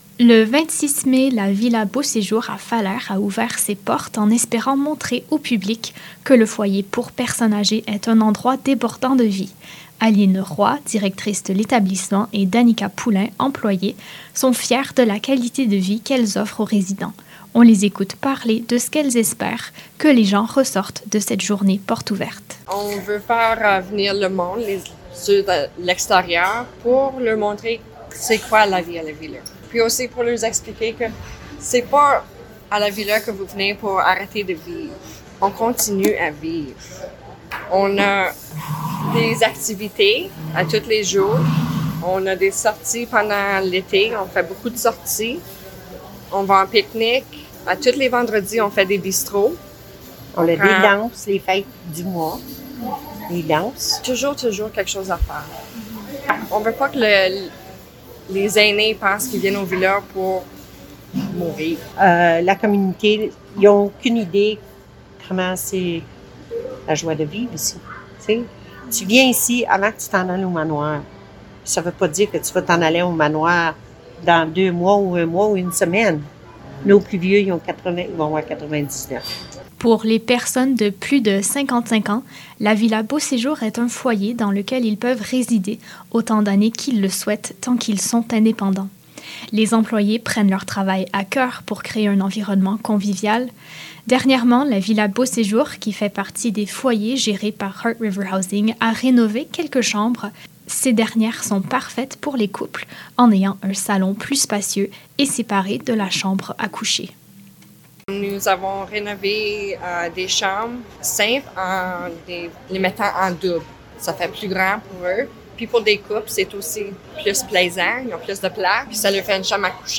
Écoutez le reportage : Télécharger Audio Précédent Article Précédent Orage à Ottawa : Hydro Ottawa travaille vers le retour à la normale Article Suivant Edmonton, une ville plus sécuritaire en 2030?